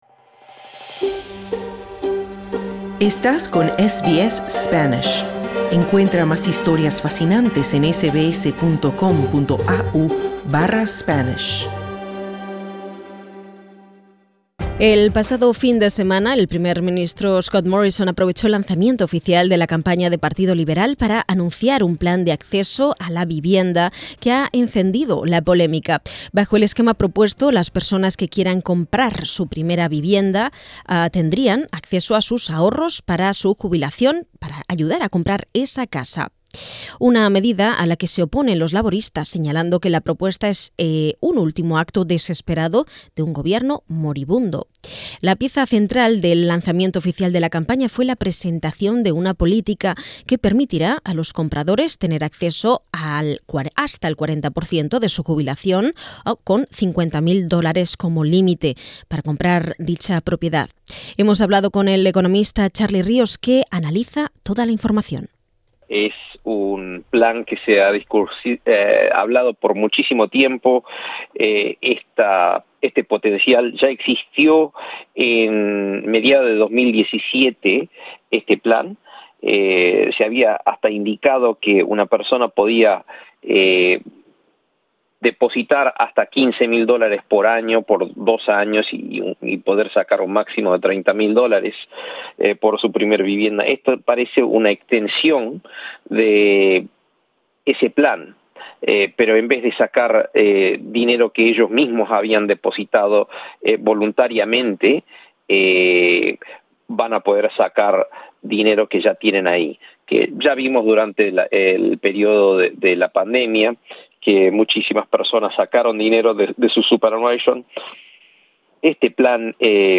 Escucha el análisis del economista